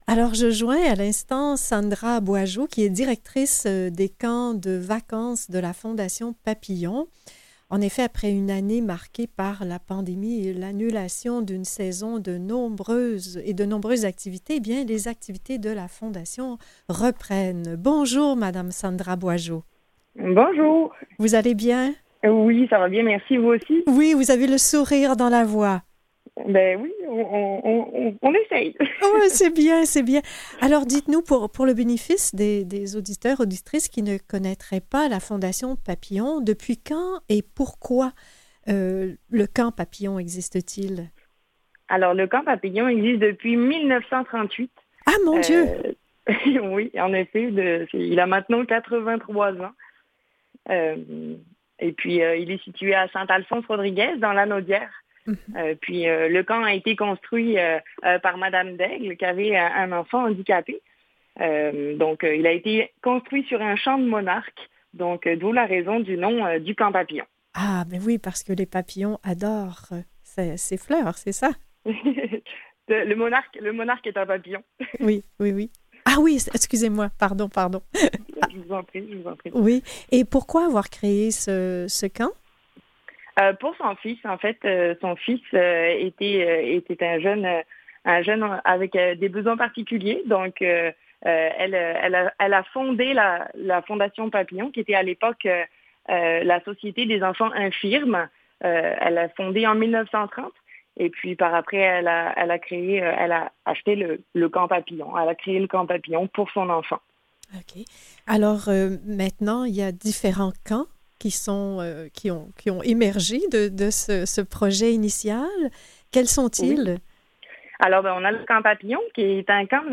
En entrevue: Après une année marquée par la pandémie et l’annulation d’une saison et de nombreuses activités, le Camp Papillon aura lieu à l’été 2021 et les inscriptions sont ouvertes.